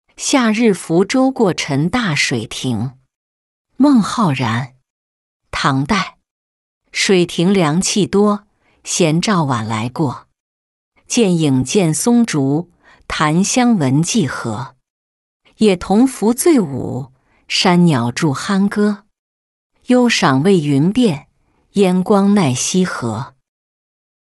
夏日浮舟过陈大水亭-音频朗读